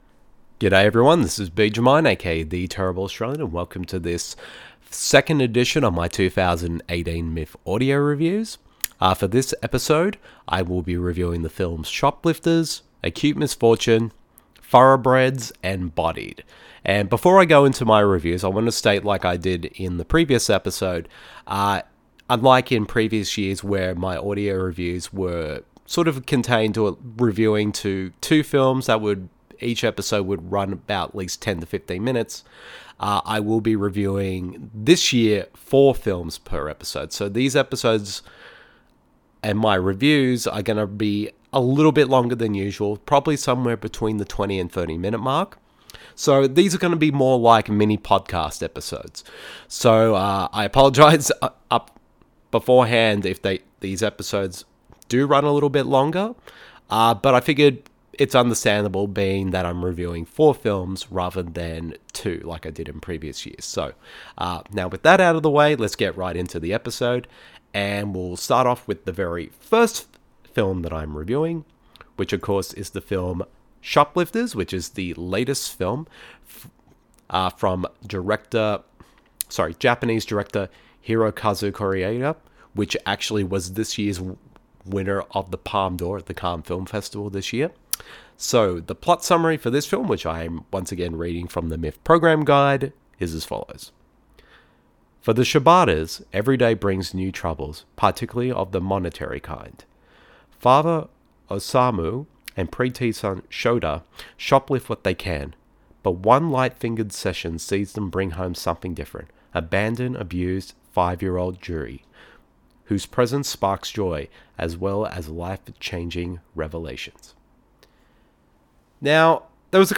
MIFF 2018 Audio Reviews #3